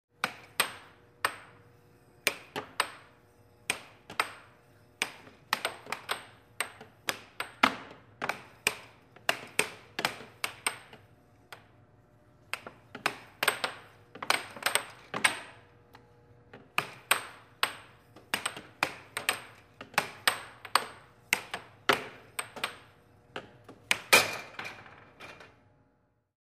Звуки спорта
Аэрохоккей увлекательная игра